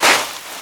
STEPS Sand, Walk 29.wav